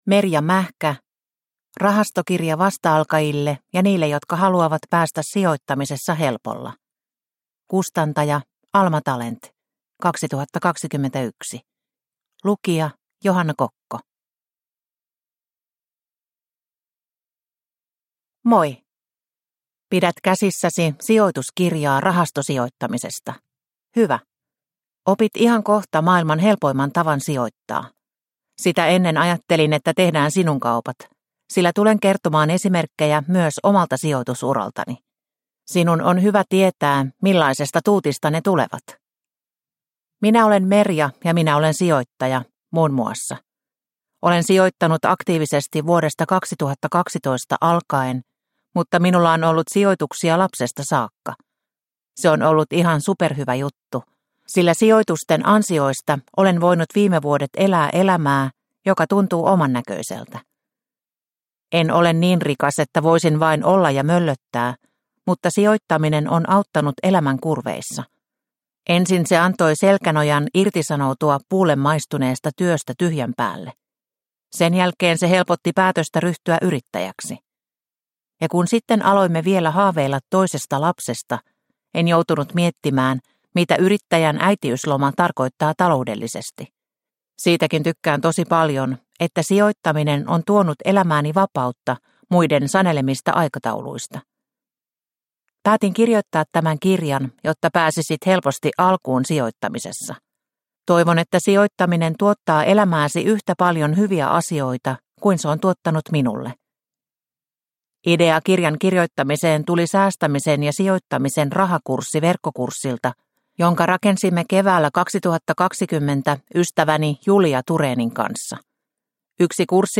Rahastokirja vasta-alkajille – Ljudbok – Laddas ner